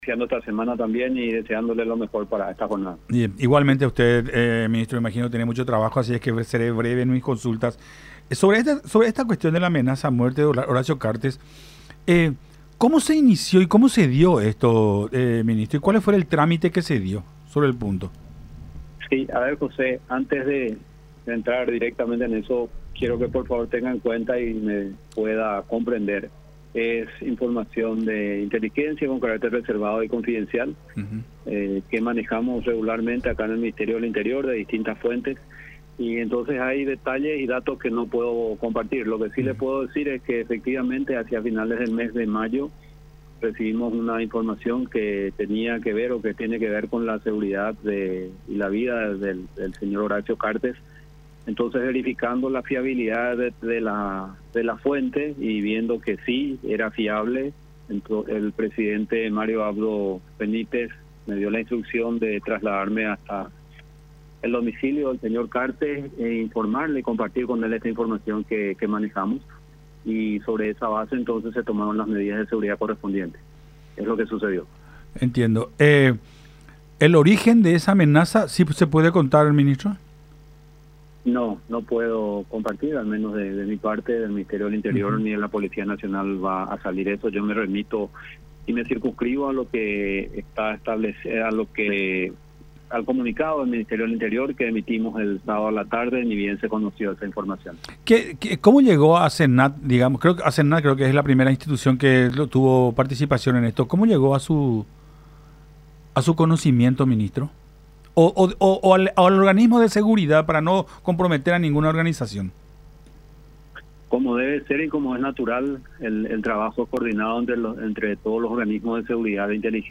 “Hacia finales de mayo recibimos una información que tenía que ver con la vida de Horacio Cartes. Verificando la fiabilidad de la fuente, el presidente de la República, Mario Abdo Benítez, me dio las instrucciones de trasladarme hasta el domicilio del señor Cartes y compartirle la información que manejamos. Sobre esa base, se tomaron las medidas de seguridad correspondientes”, expuso González en charla con Nuestra Mañana a través de radio La Unión y Unión TV.
05-FEDERICO-GONZALEZ.mp3